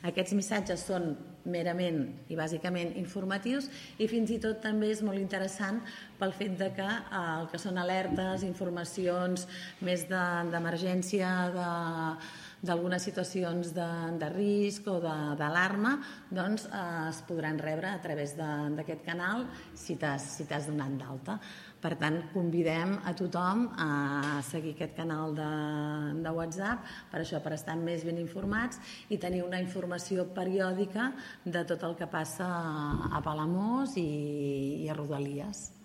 Aquesta aplicació ha de permetre també l’enviament d’alertes, sempre d’informació de servei, especialment per situacions d’emergència o per afectacions rellevants en subministraments bàsics, en la mobilitat o altres qüestions i situacions que requereixin la màxima difusió, tal com explica la regidora de comunicació i participació ciutadana, Núria Botellé.